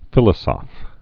(fĭlə-sŏf, fēlô-zôf)